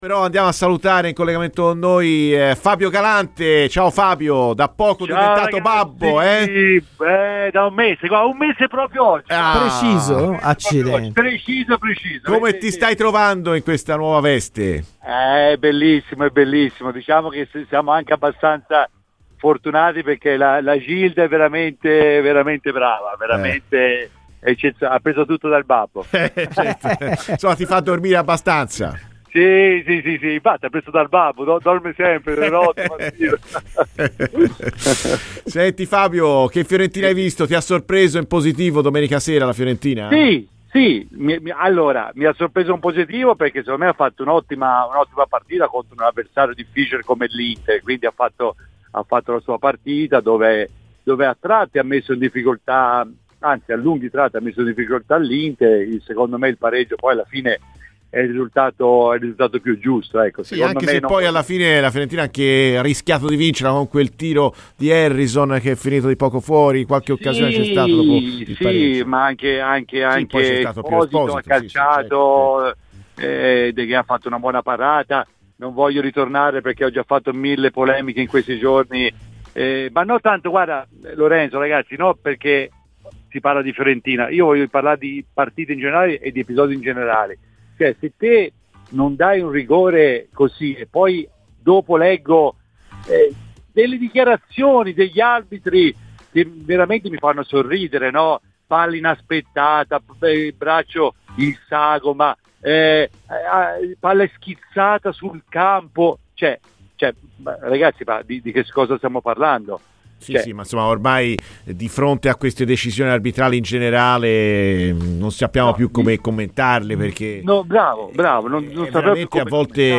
Fabio Galante, ex difensore tra le altre di Inter e Livorno, è intervenuto a Radio FirenzeViola nel corso della trasmissione "Viola amore mio".